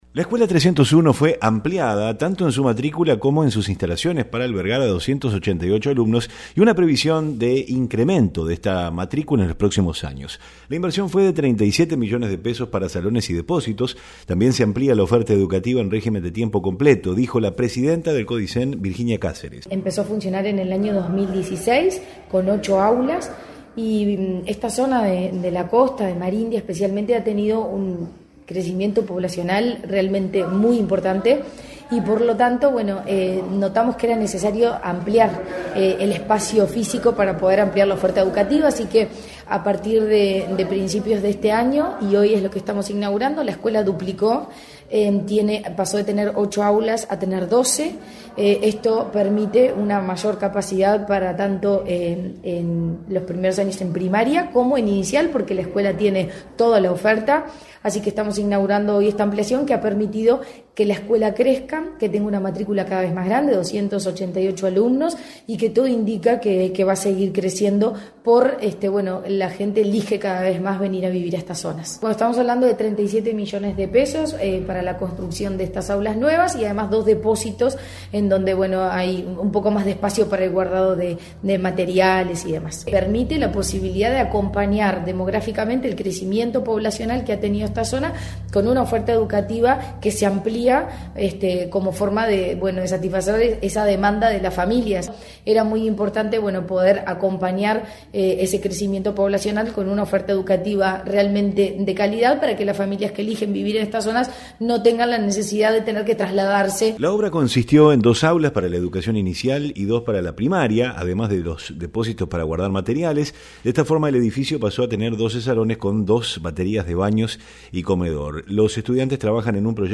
También "se amplía la oferta educativa en régimen de tiempo completo" dijo la presidenta del Codicen, Virginia Cáceres.
REPORTE-ESCUELA-301.mp3